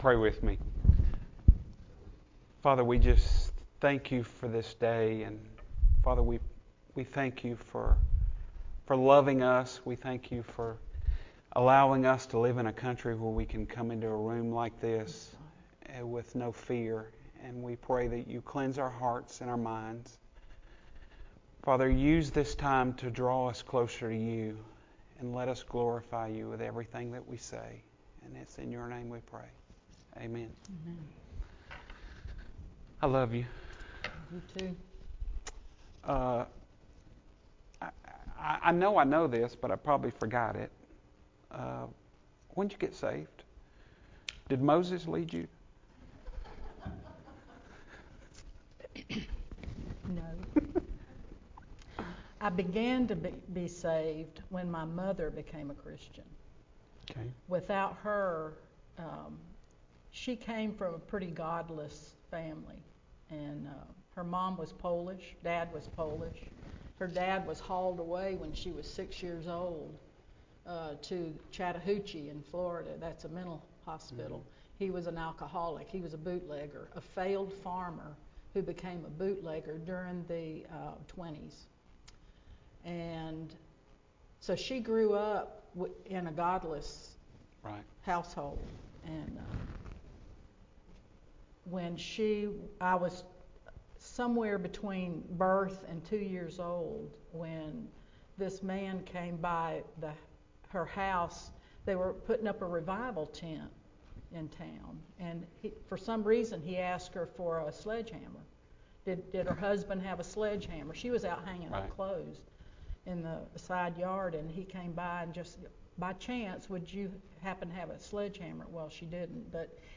Audio Sermon Only